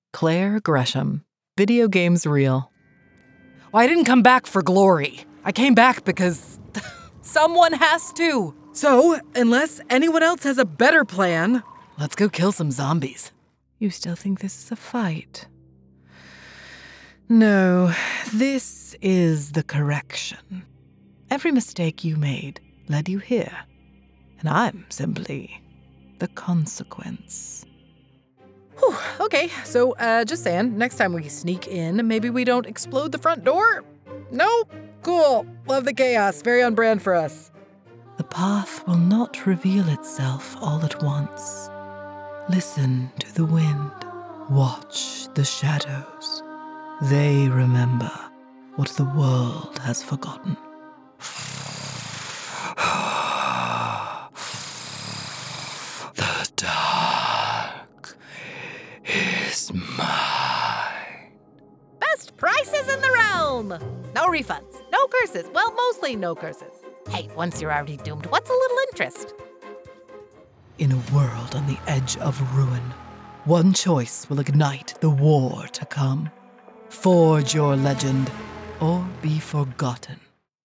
Videogames Multi-Character Reel
General American, US Southern (various dialects), British RP
Middle Aged